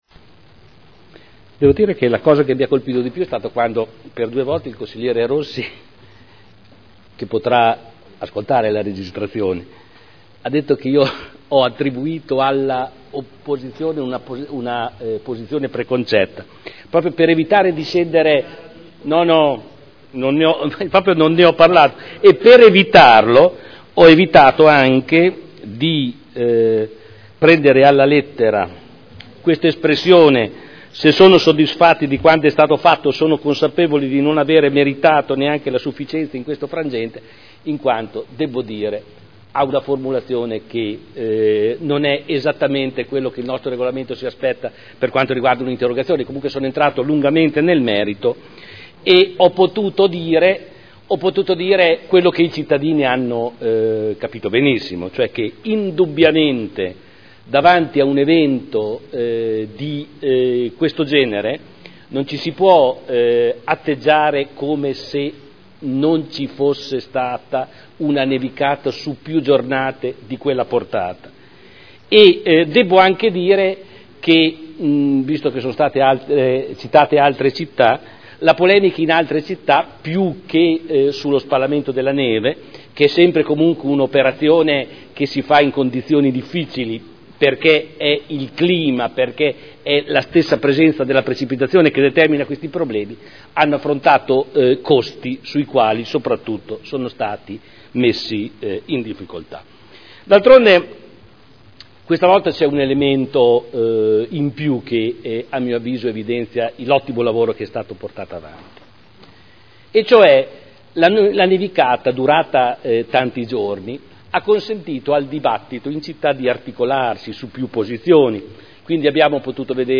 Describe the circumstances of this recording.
Seduta del 16 aprile. Audio Consiglio Comunale